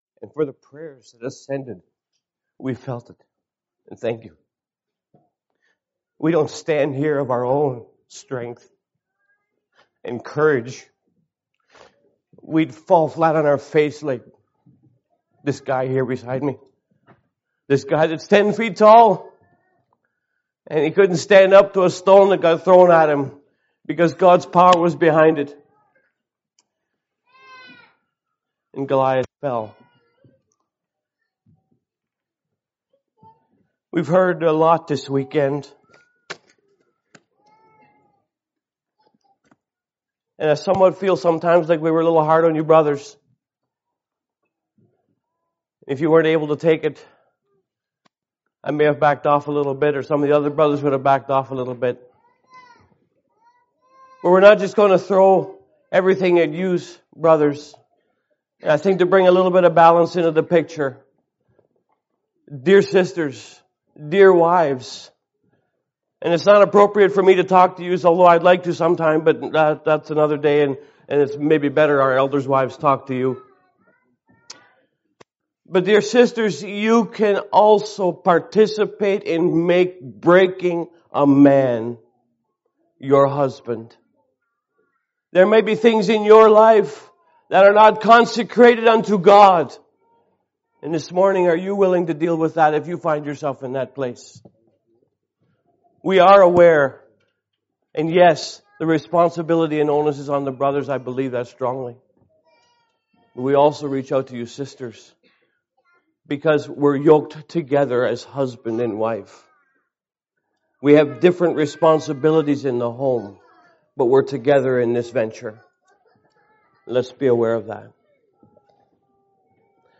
Service Type: Sunday Sermons